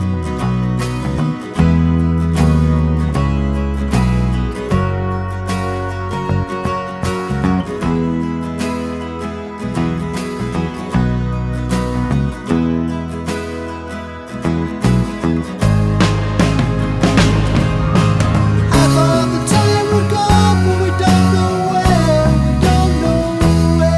With Harmony Pop (1960s) 3:58 Buy £1.50